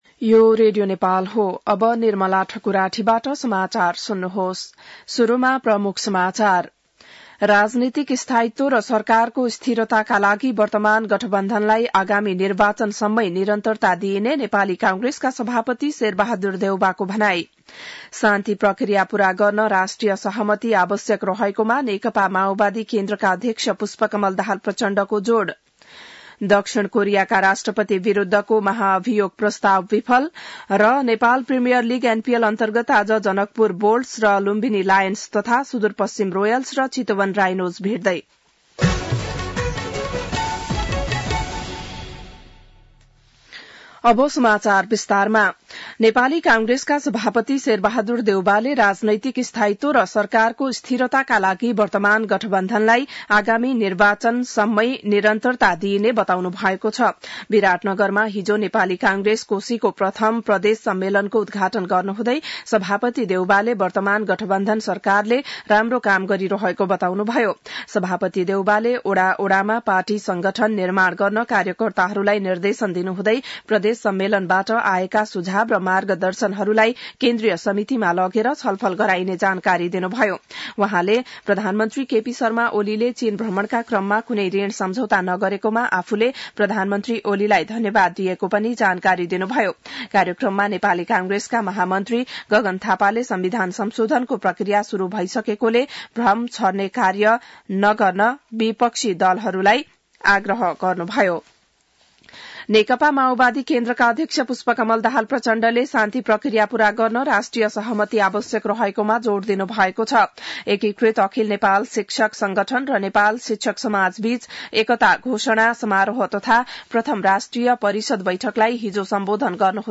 बिहान ९ बजेको नेपाली समाचार : २४ मंसिर , २०८१